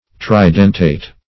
tridentate - definition of tridentate - synonyms, pronunciation, spelling from Free Dictionary
Search Result for " tridentate" : The Collaborative International Dictionary of English v.0.48: Tridentate \Tri*den"tate\, Tridentated \Tri*den"ta*ted\, a. [NL. tridentatus.